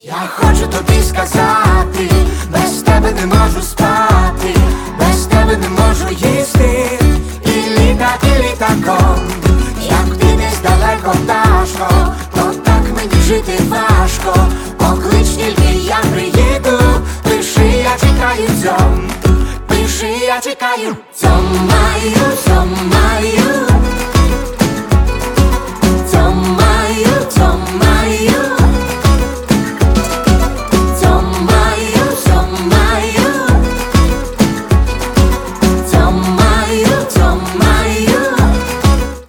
• Качество: 128, Stereo
веселые
заводные
дуэт
мужской и женский вокал